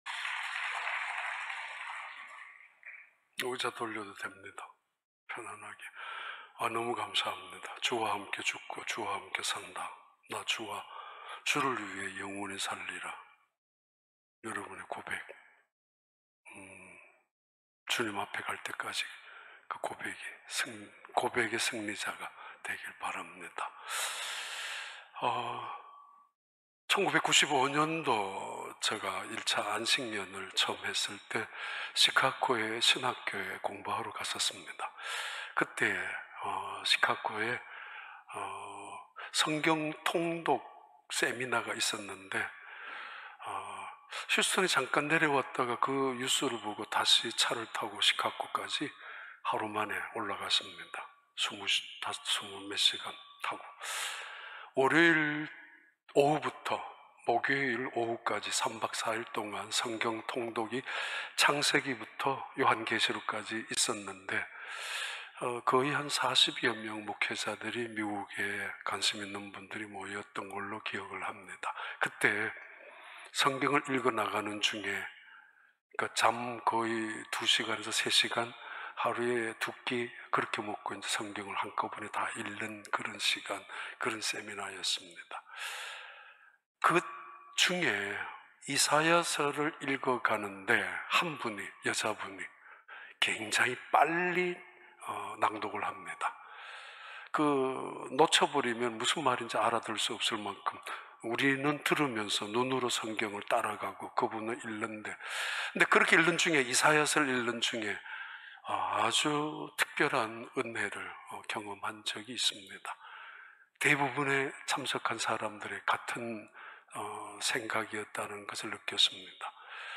2021년 5월 16일 주일 4부 예배